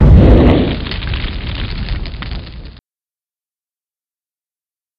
nalpalm_missile_hit.wav